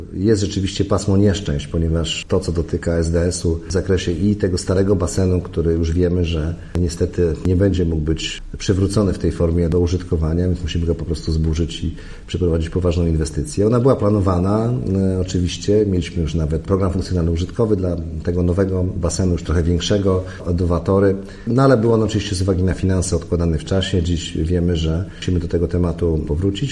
– Mówi Piotr Krzystek – Prezydent Szczecina.